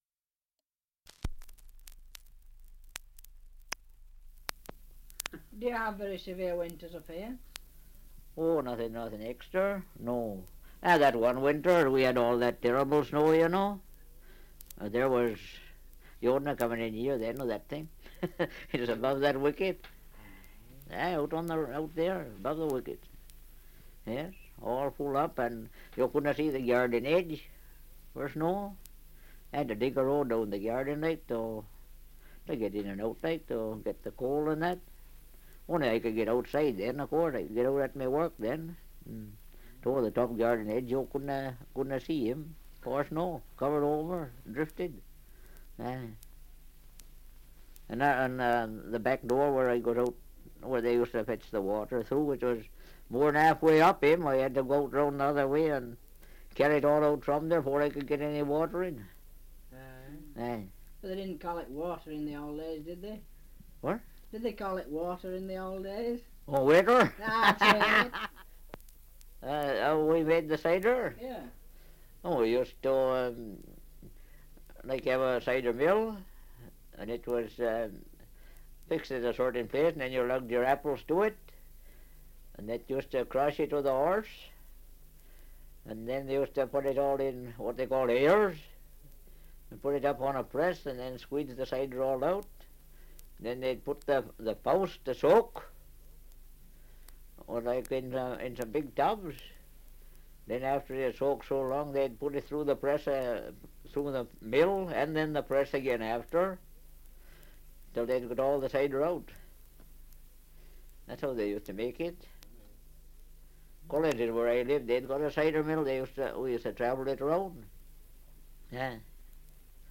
Survey of English Dialects recording in Clun, Shropshire
78 r.p.m., cellulose nitrate on aluminium